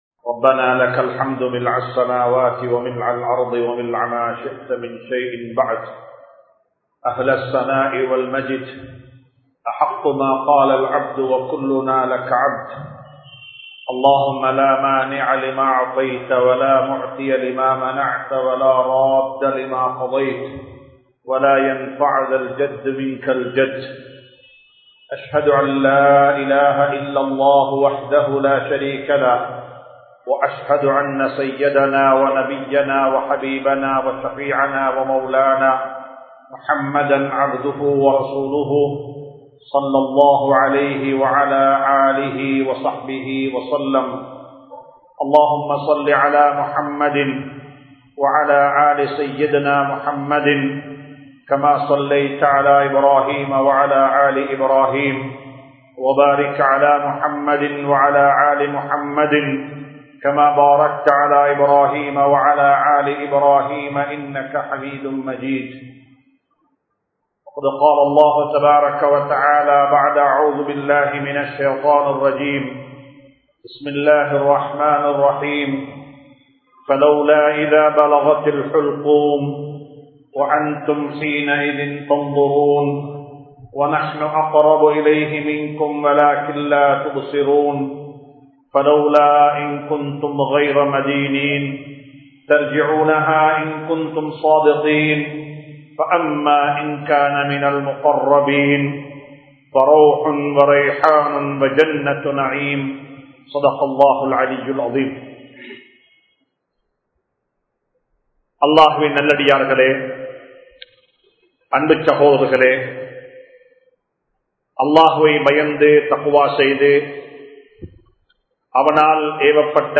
Manithan Marukka Mudiyatha Maranam (மனிதன் மறுக்க முடியாத மரணம்) | Audio Bayans | All Ceylon Muslim Youth Community | Addalaichenai
Majma Ul Khairah Jumua Masjith (Nimal Road)